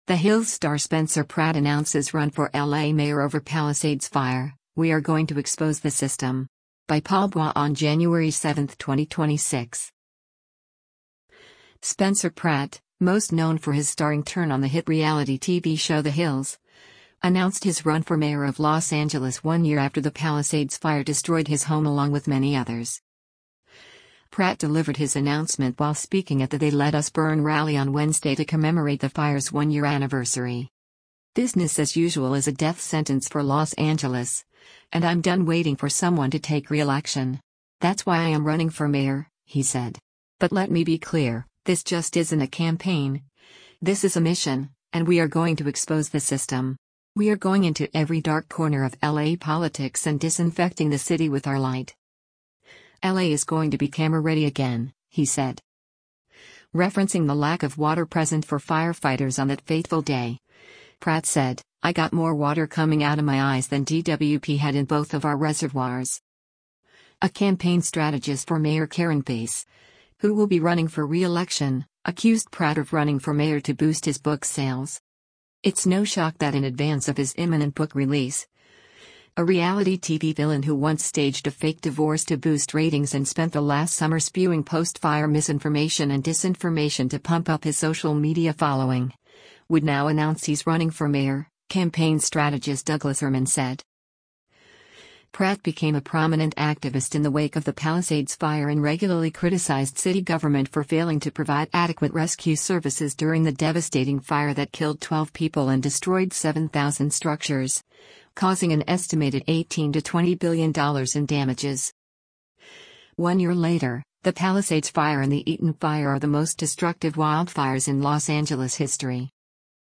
Pratt delivered his announcement while speaking at the “They Let Us Burn” rally on Wednesday to commemorate the fire’s one year anniversary.